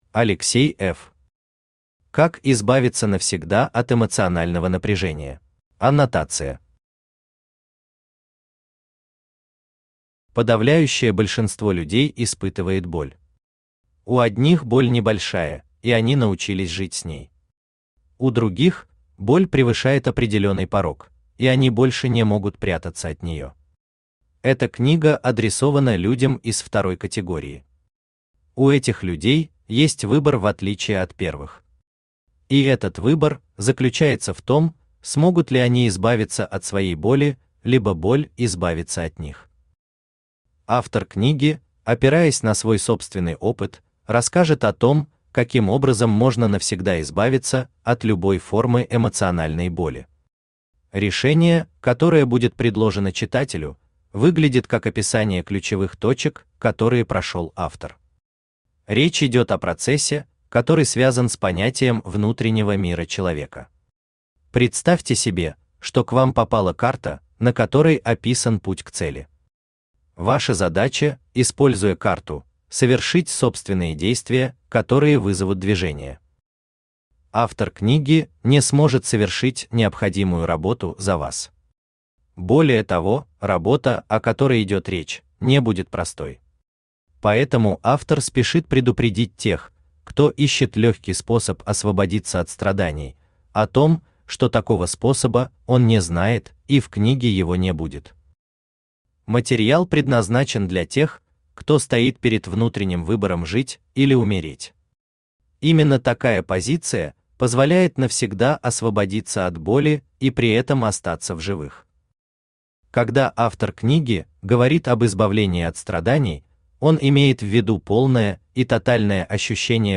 Aудиокнига Как избавиться навсегда от эмоционального напряжения Автор Алексей F. Читает аудиокнигу Авточтец ЛитРес.